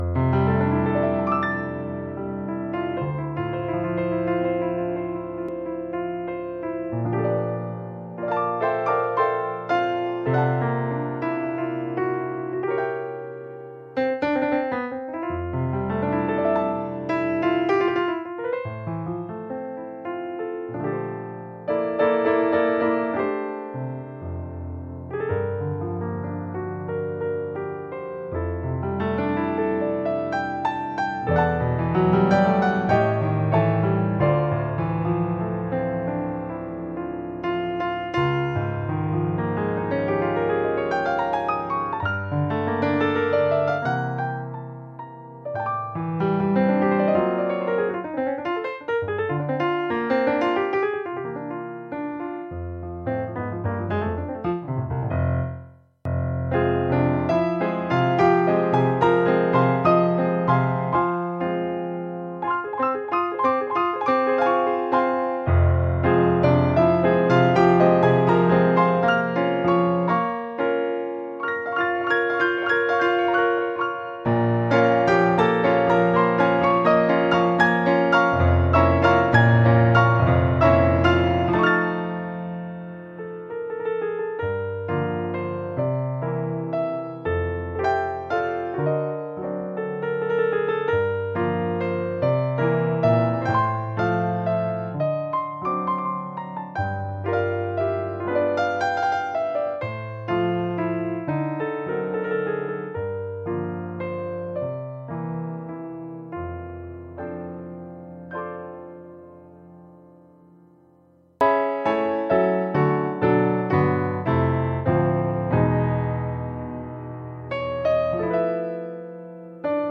ur-Bright Grand Yamaha.mp3